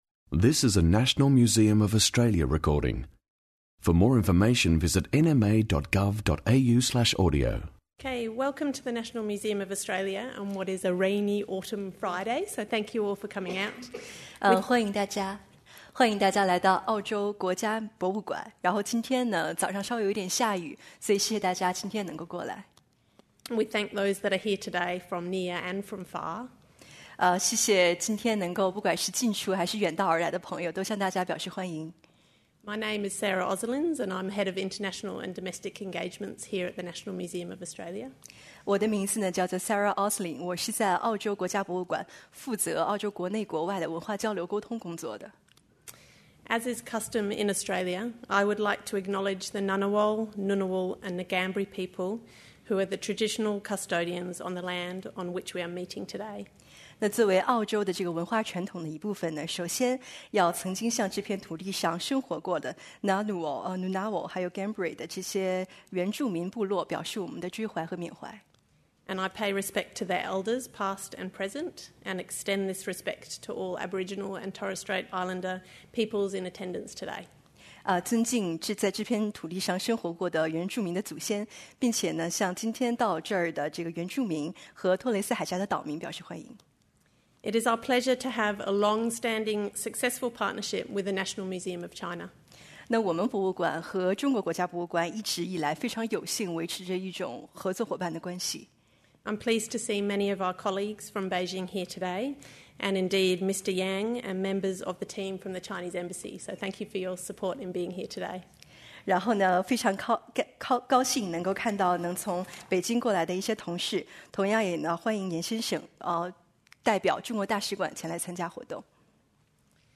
The lecture is conducted in Chinese and translated by a live interpreter.